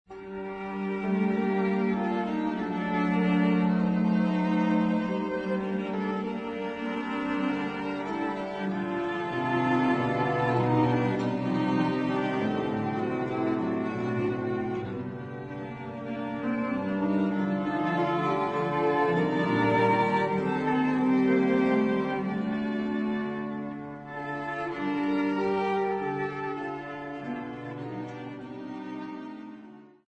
Classical music South Africa
Country dancing South Africa
field recordings
Country dances song with classical instruments accompaniment.